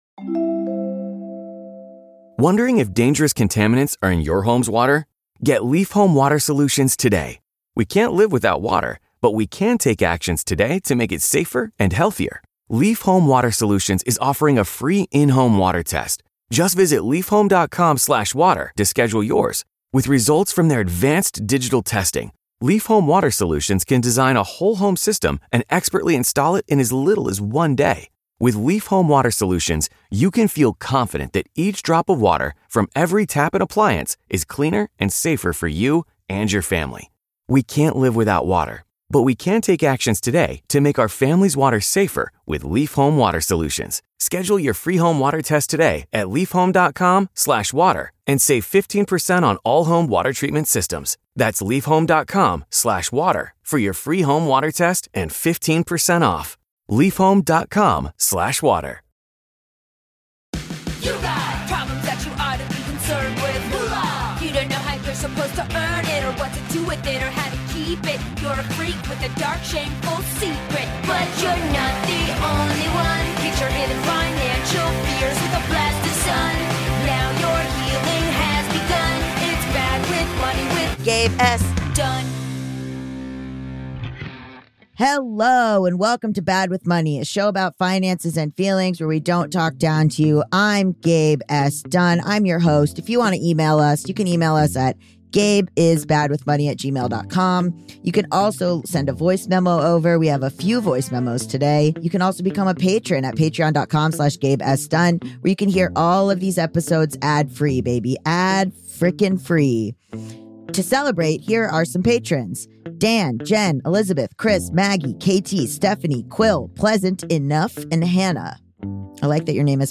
A listener calls in with some encouragement for people who are anxious about opening their mail. A long email comes in about what to know when buying a home.